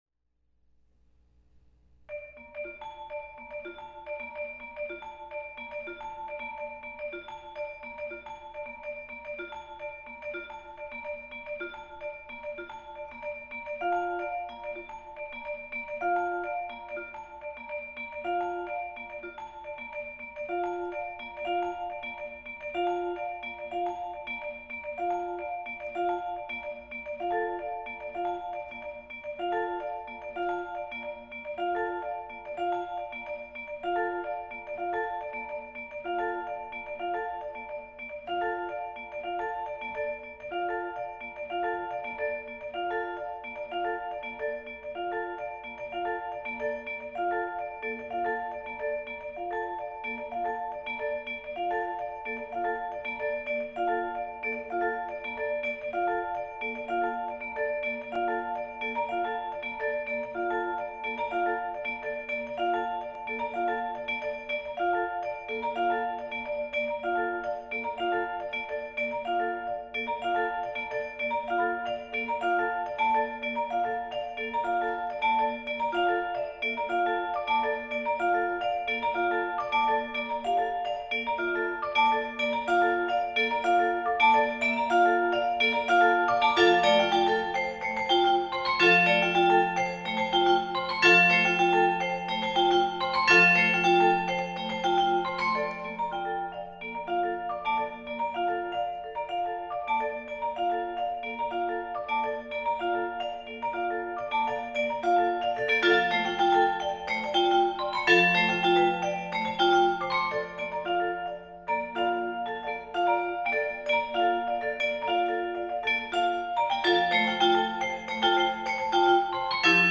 Genre: Duet for 2 Vibraphones
2 Vibraphones